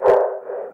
breath4gas.ogg